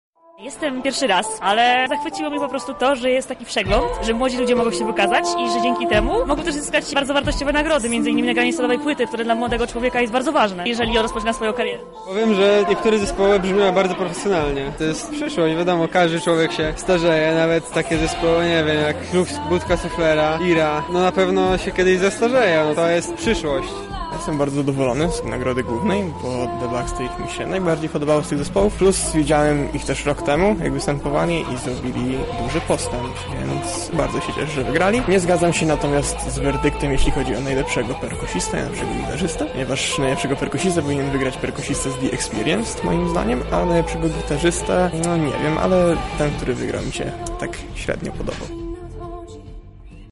Po raz 30. mogliśmy zobaczyć pojedynek na wokal i instrumenty. Dźwięki rocka i folku wybrzmiały na scenie Centrum Spotkania Kultur.
Na miejscu była nasza reporterka: